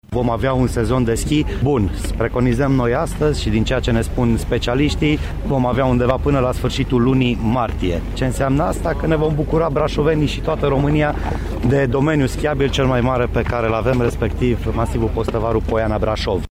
Pe pârtia Bradul va funcționa și instalația de nocturnă, astfel că turiștii se pot bucura de schi și după lăsarea întunericului, spune administratorul public al municipiului Brașov, Miklos Gantz: